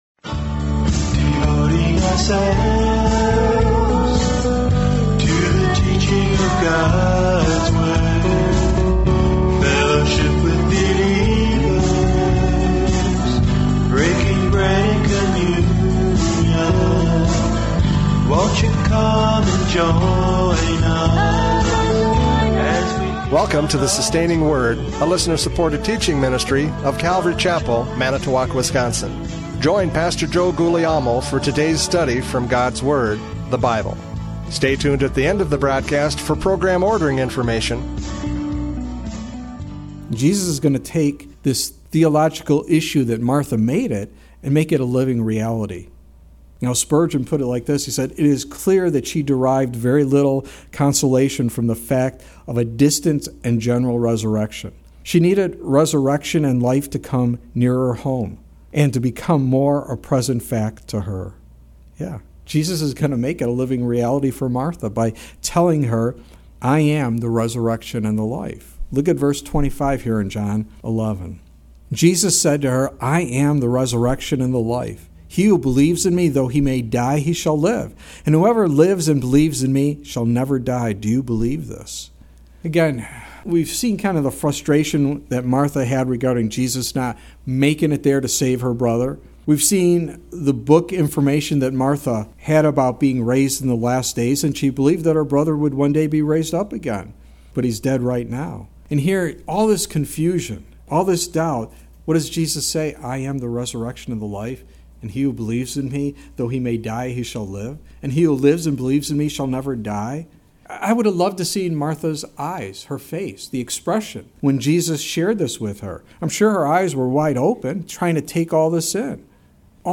John 11:17-27 Service Type: Radio Programs « John 11:17-27 Resurrection Life!